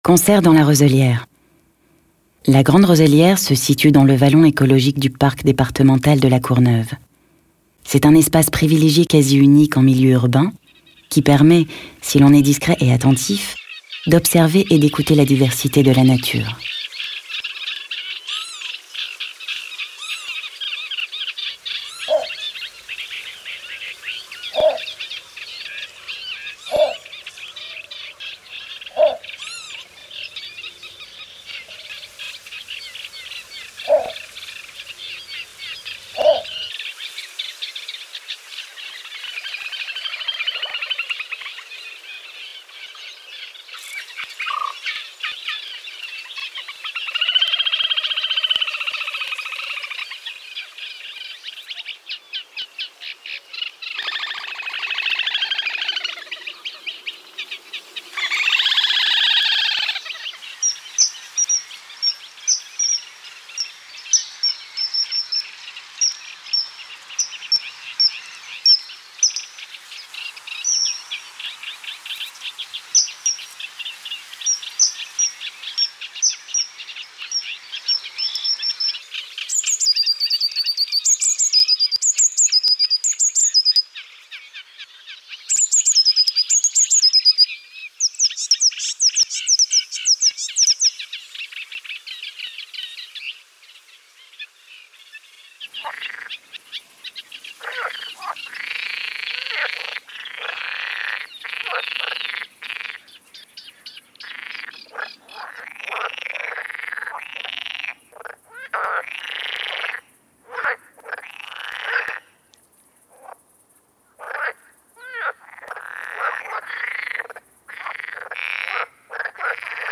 Index of / stock ancien/6/09_le_carnaval/sons oiseaux